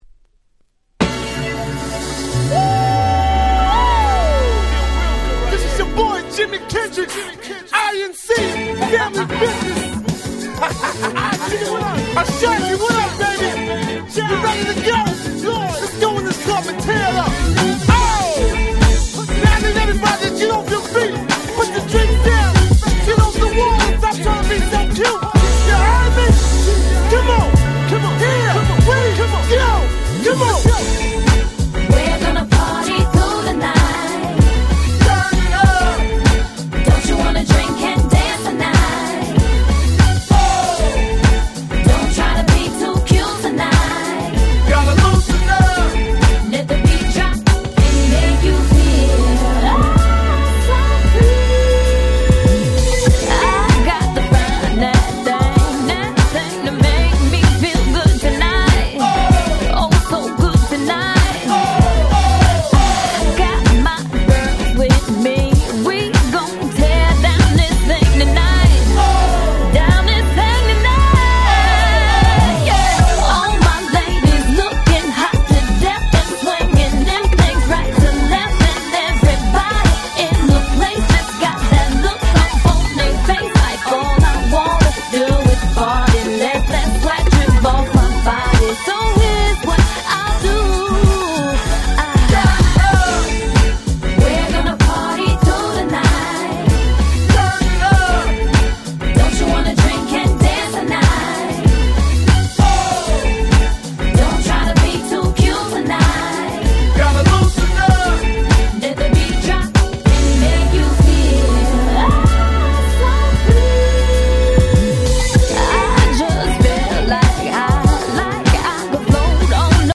※試聴ファイルは別の盤から録音してございます。